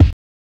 SPILL CRACKL.wav